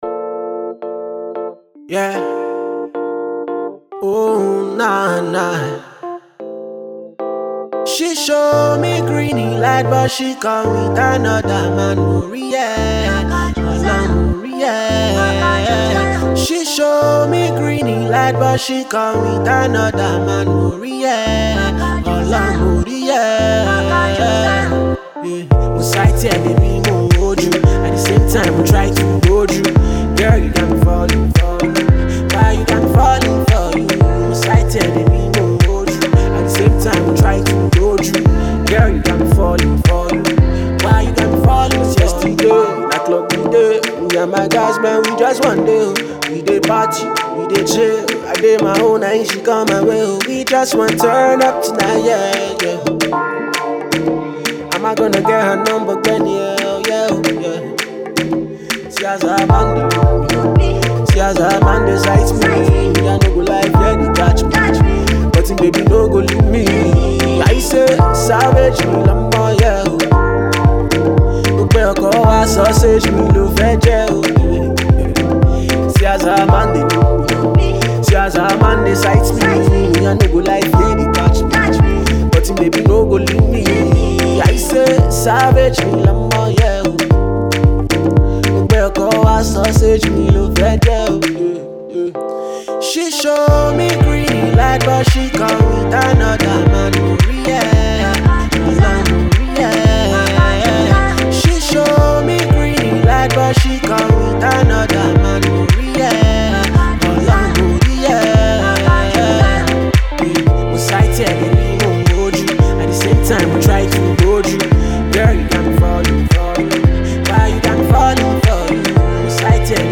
This is surely pleasing to the ear and will get you dancing.